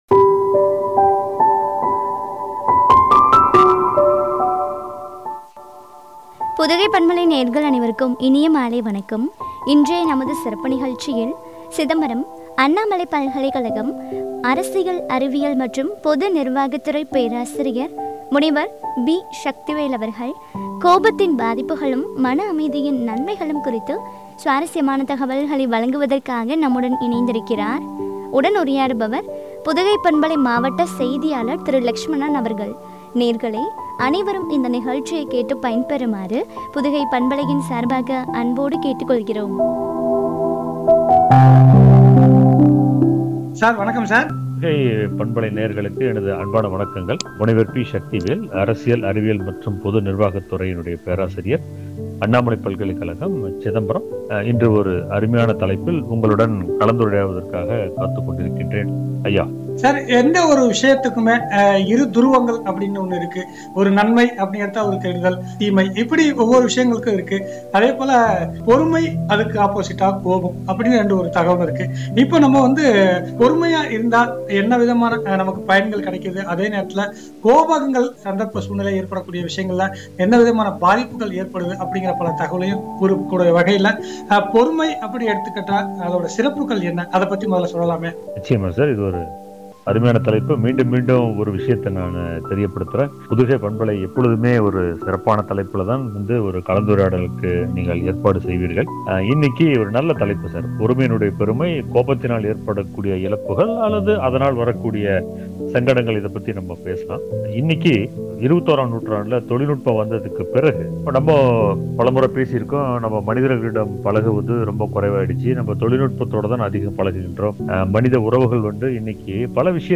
மன அமைதியின் நன்மைகளும்” குறித்து வழங்கிய உரையாடல்.